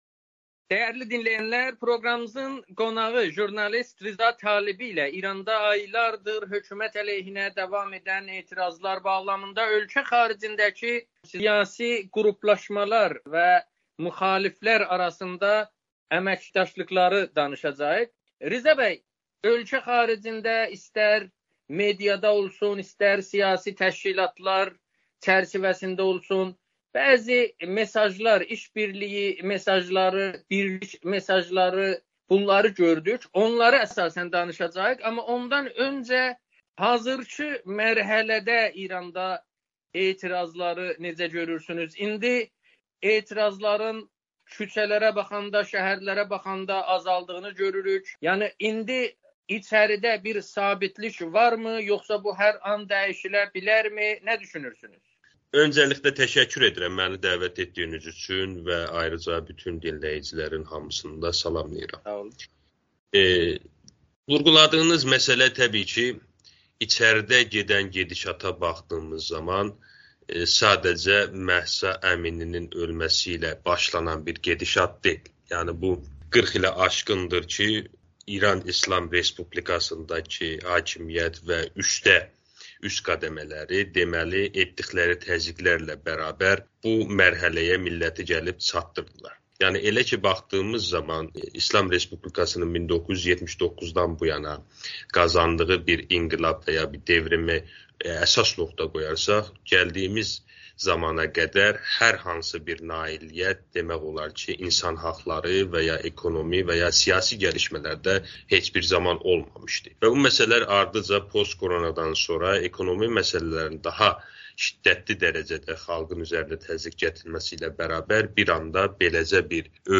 müsahibədə